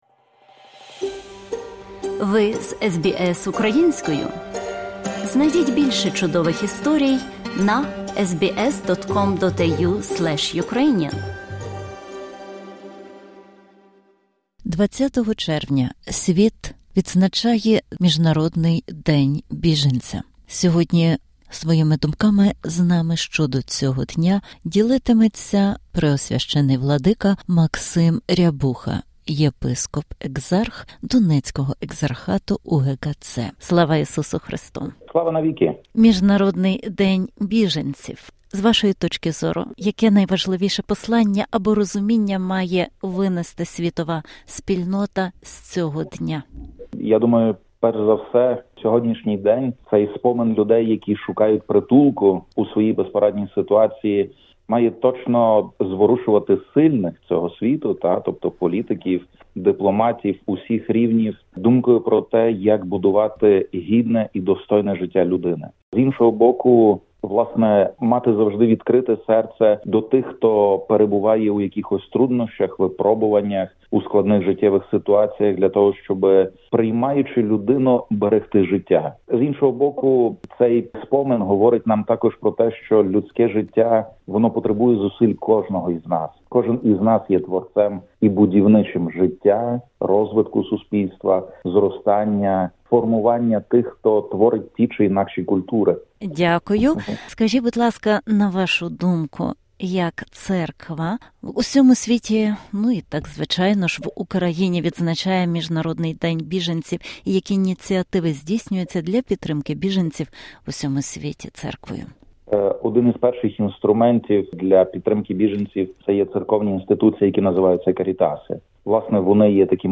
Інтерв'ю з владикою Максимом Рябухою, єпископом-екзархом Донецького екзархату Української Греко-Католицької Церкви (УГКЦ), під час його нещодавньої місіонерської подорожі.